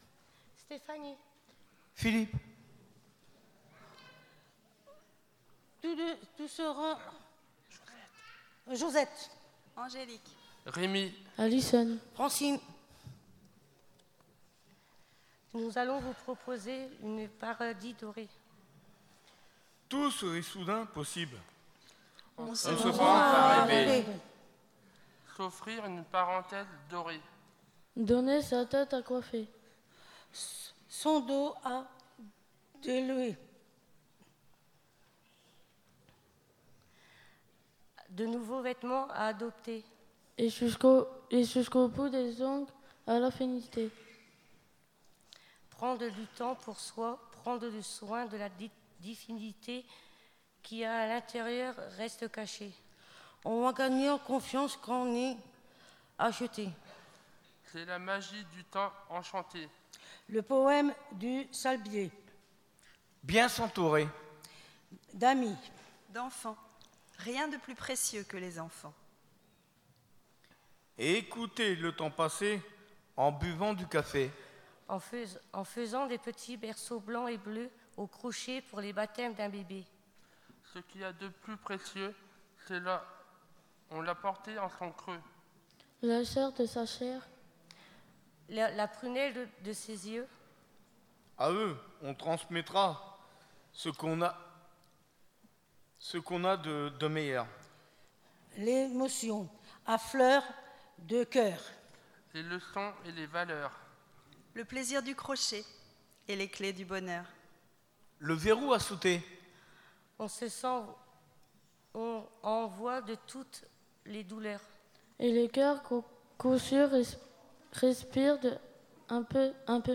lectures audio